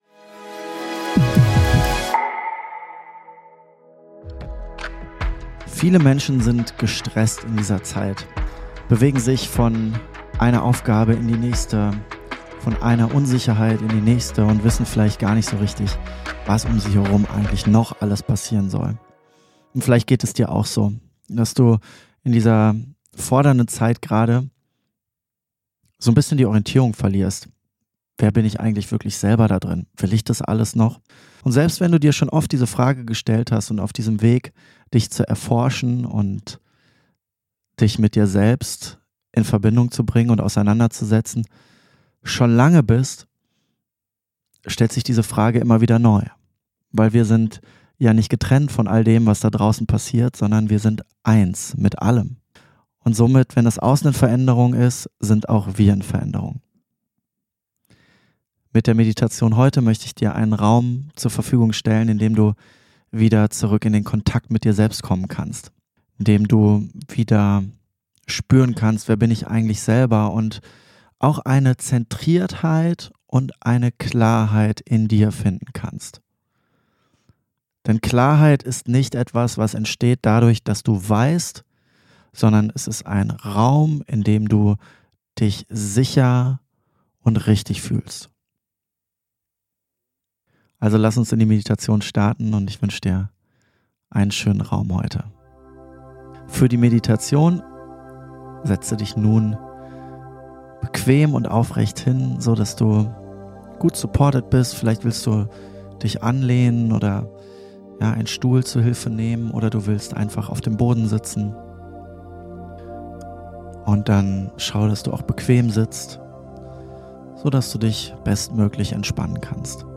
🧘‍♀Erinnere Dich an das Licht in Dir🧘‍♂ - LIVE MEDITATION